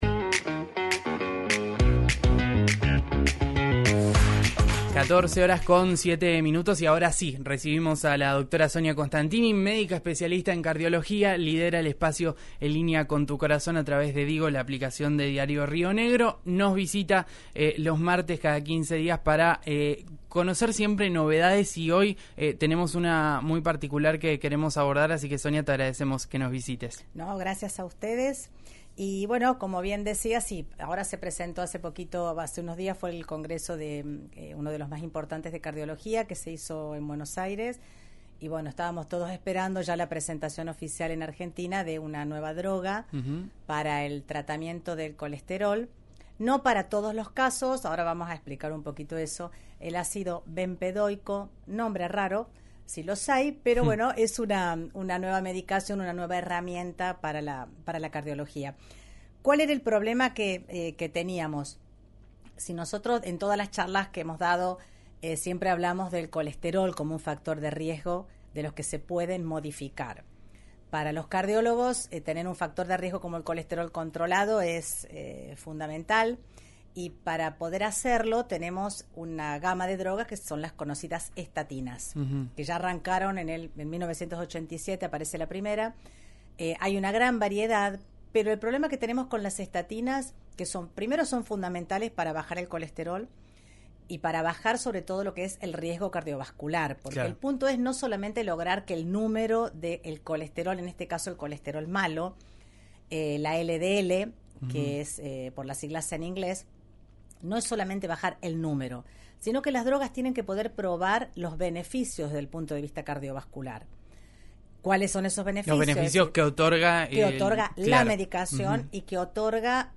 El ácido bempedoico reduce el riesgo de eventos cardiovasculares como el ataque cardíaco o un accidente cerebrovascular. Charlamos en RÍO NEGRO RADIO